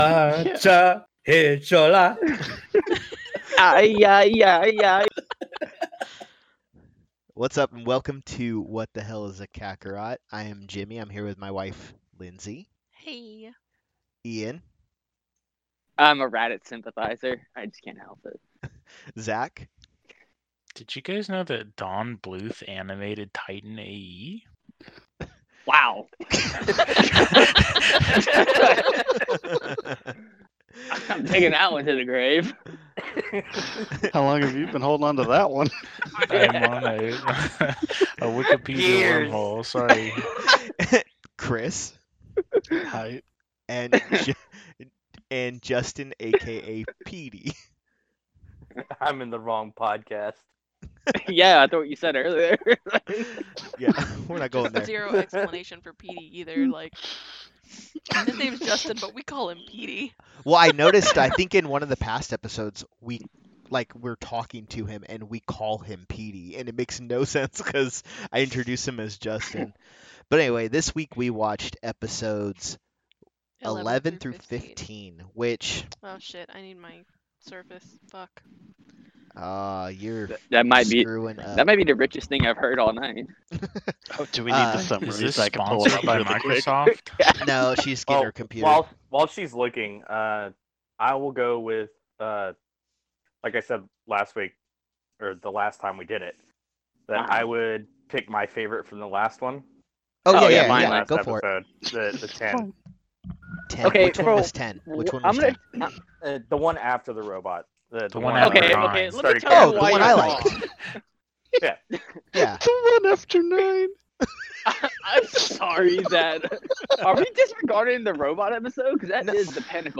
Join us as he and five childhood fans of the show sit down to discuss the nuances of the episodes 11-15 of this classic anime.